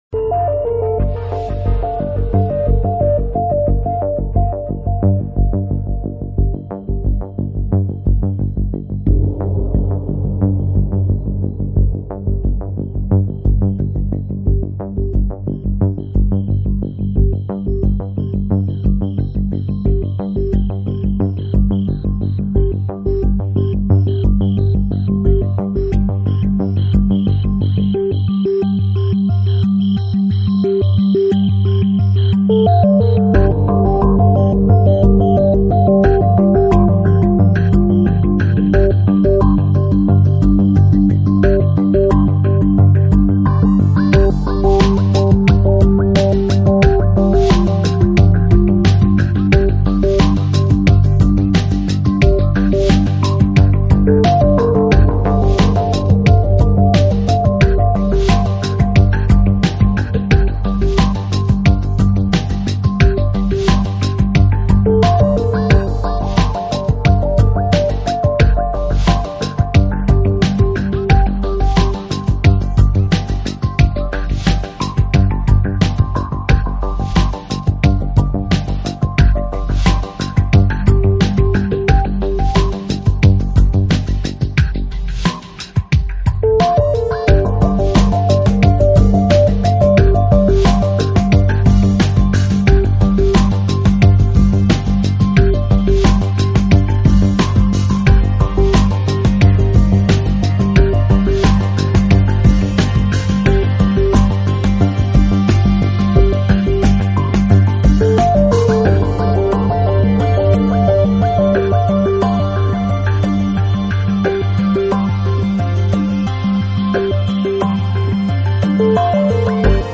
Style: Ambient, Lounge, Chillout